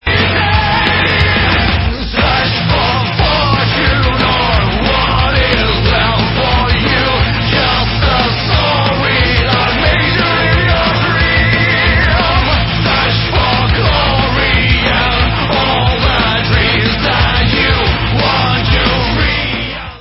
Rock/Progressive